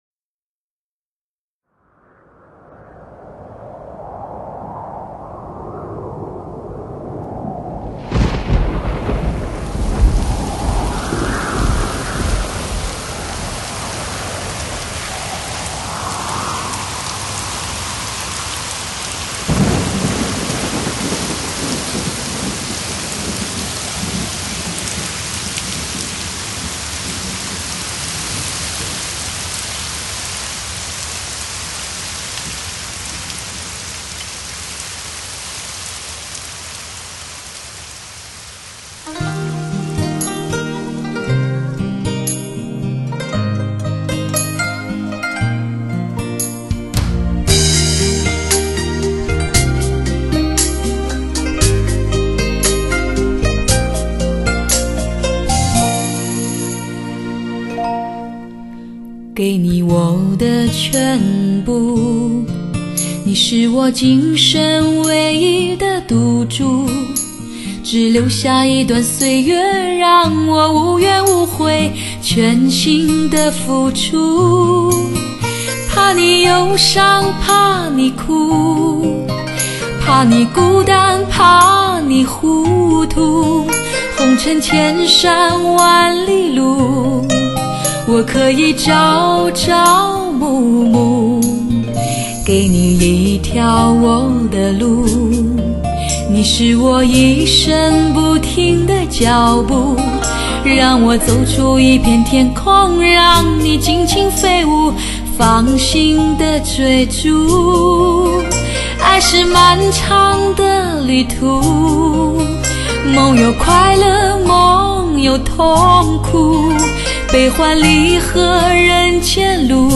采用最新美国DTS-ES6 1顶级编码器，带来超乎想象震撼性6.1环绕声体验。